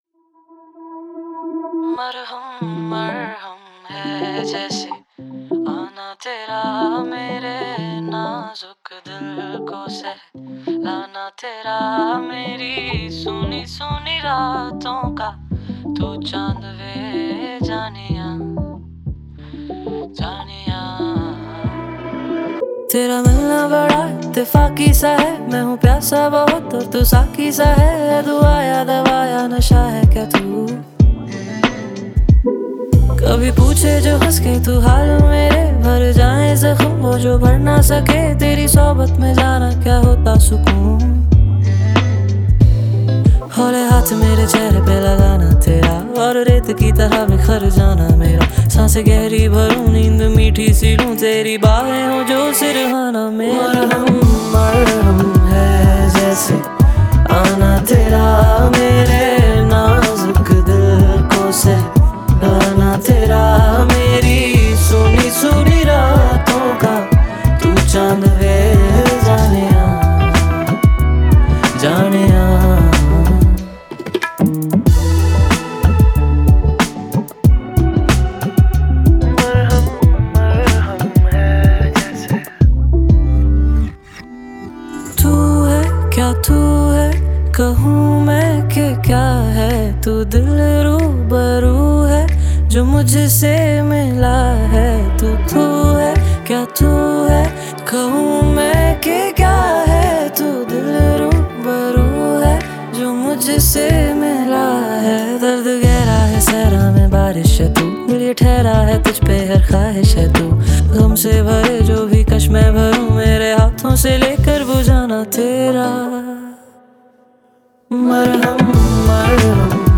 IndiPop